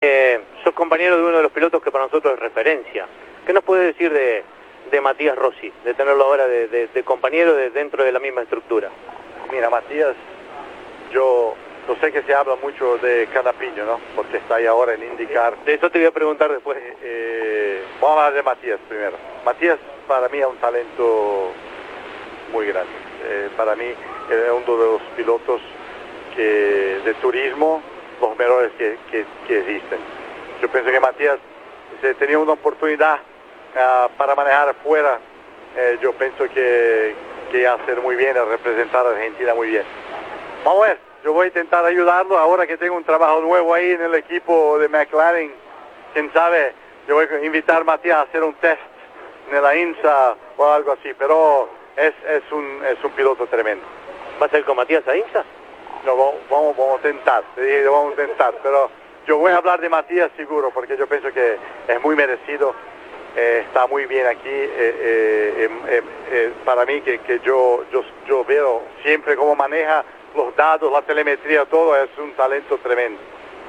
Allí compite con Matías Rossi, y en diálogo con Campeones destacó el trabajo del de Del Viso como piloto.